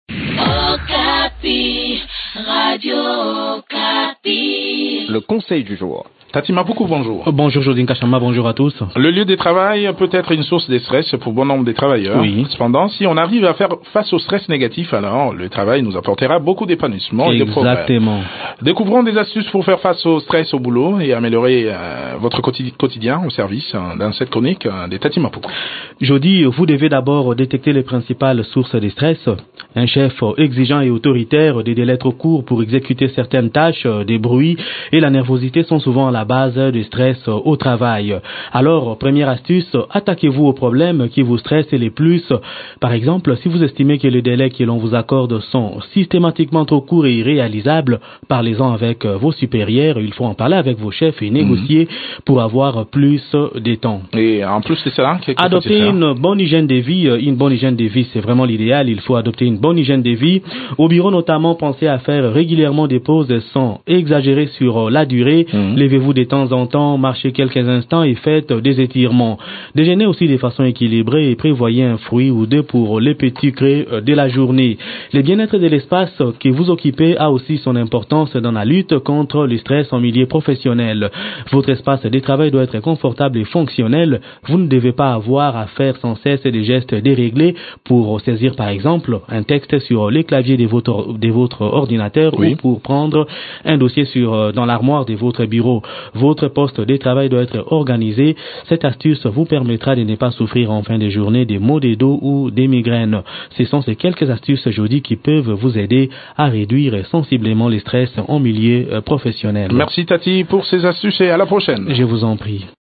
Découvrez des astuces pour faire face au stress au boulot et améliorer votre quotidien au service dans cette chronique